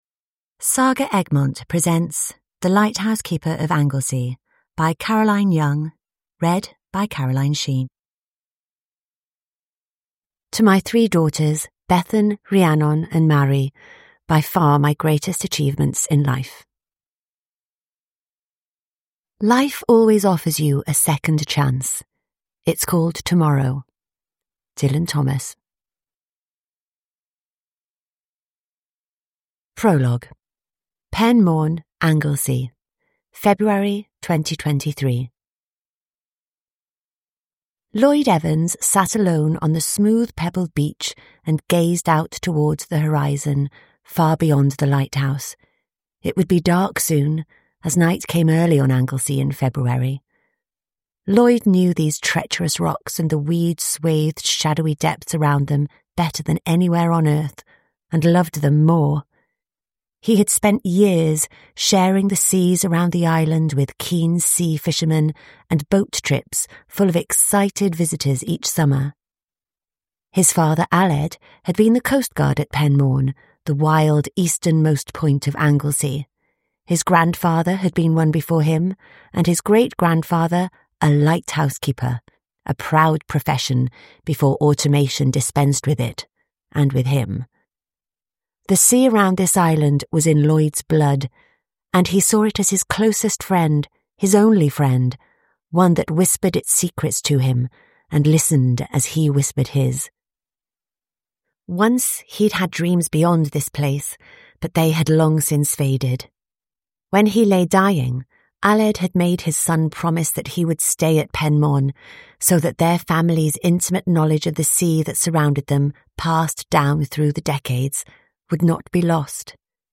The Lighthouse Keeper of Anglesey: An inspiring tale of family secrets and starting over (ljudbok) av Caroline Young